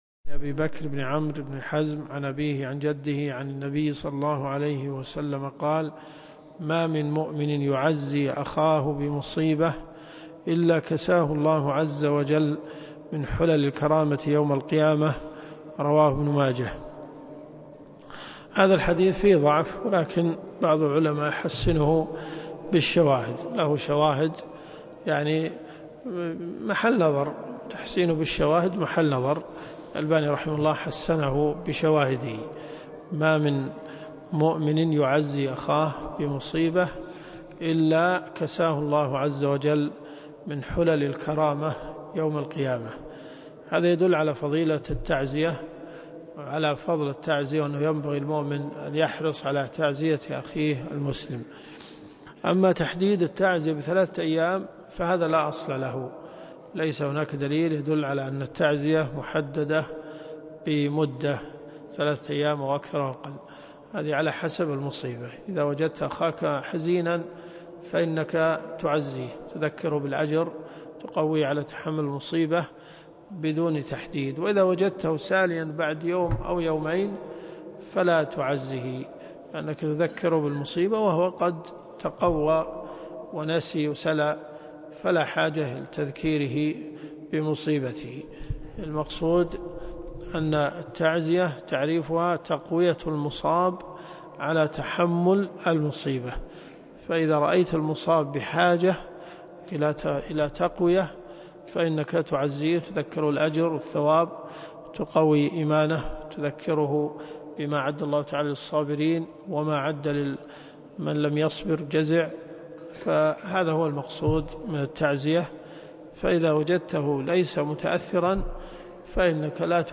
الدروس الشرعية
7 - 1433.3 . المنتقى من أخبار المصطفى . كتاب الجنائز . من حديث 1926 -إلى- حديث 1957 . الرياض . حي أم الحمام . جامع الملك خالد